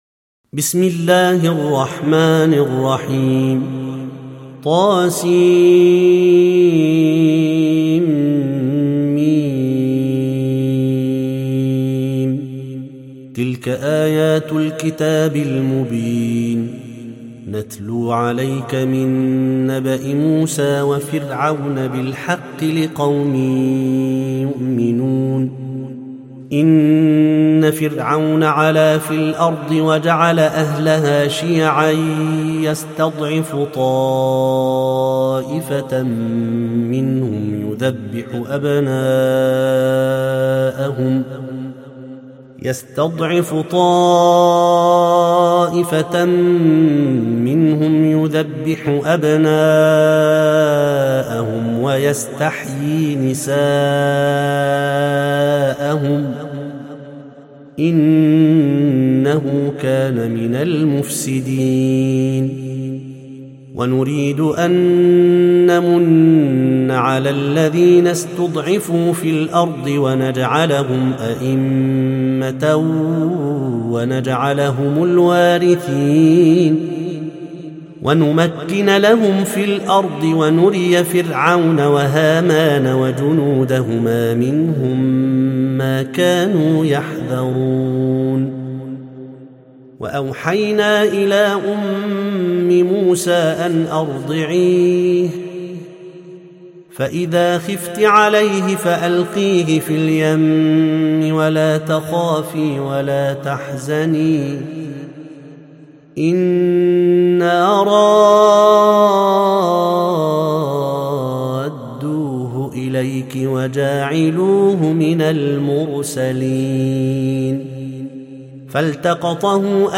المصحف المرتل (برواية حفص عن عاصم)
التصنيف: تلاوات مرتلة